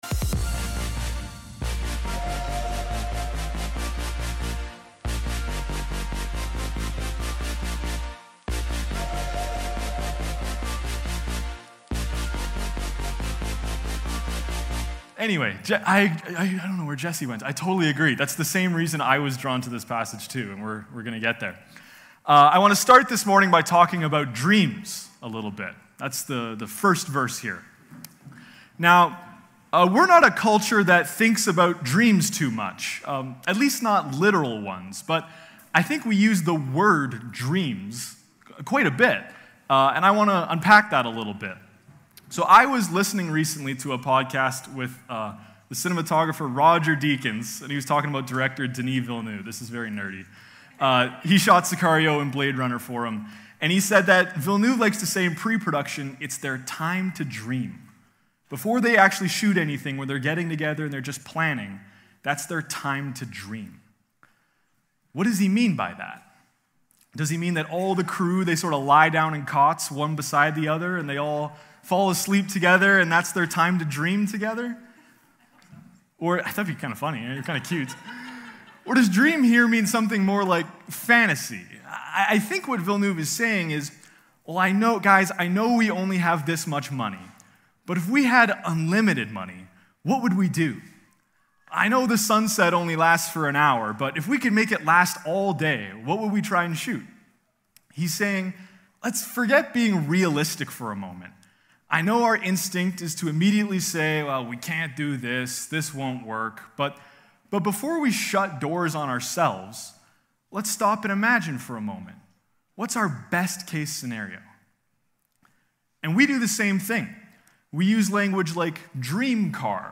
September-14-Service.mp3